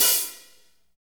HAT P B S0HR.wav